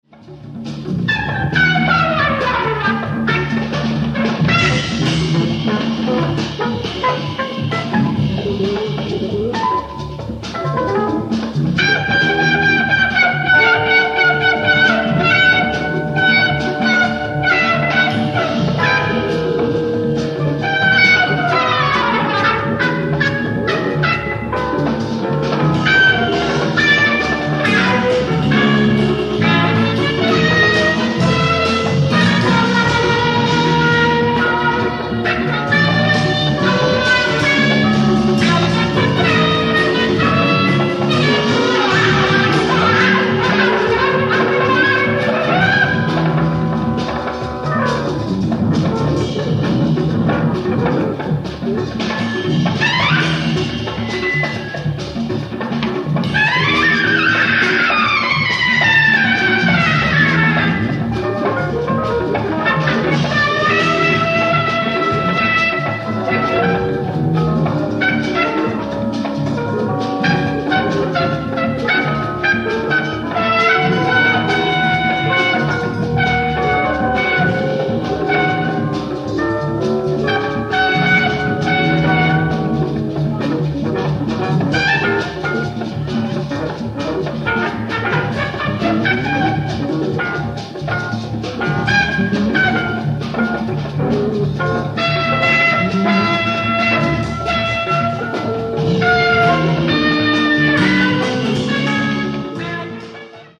BAND RECORDING ON THE STAGE